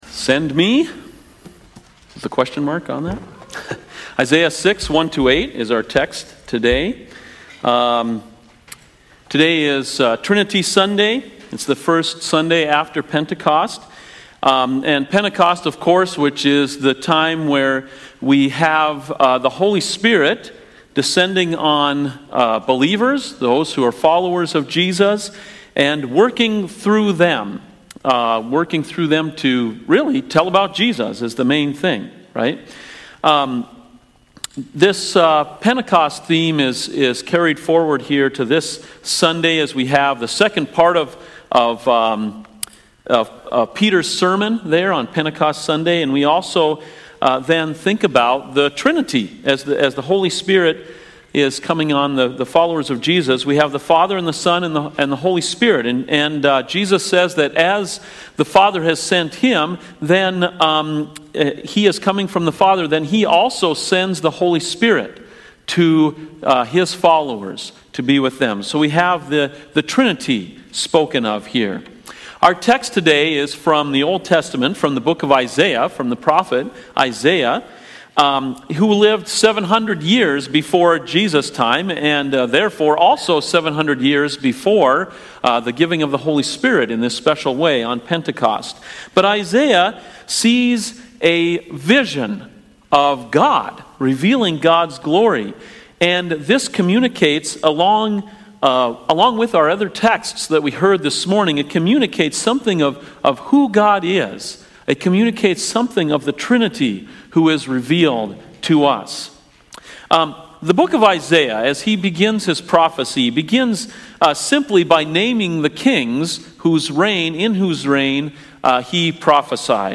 CoJ Sermons Send Me?